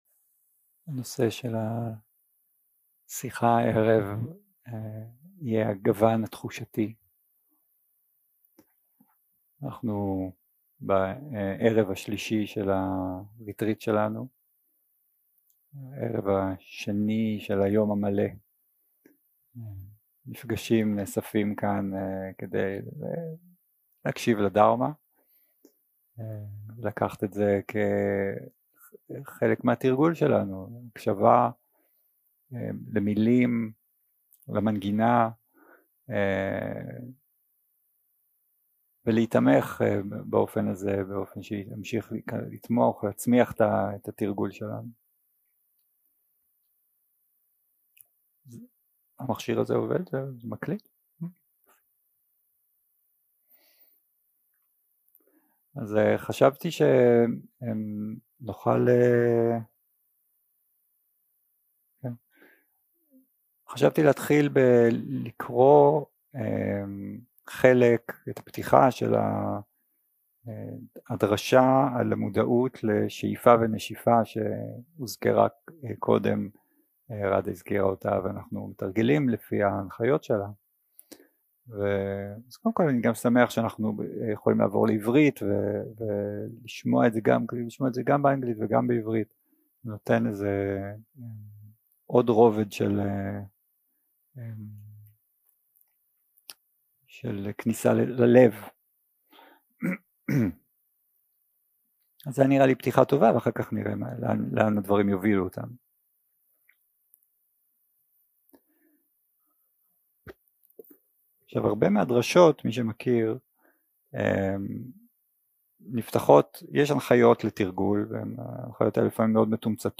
יום 3 – הקלטה 7 – ערב - שיחת דהארמה - וודאנה
Dharma type: Dharma Talks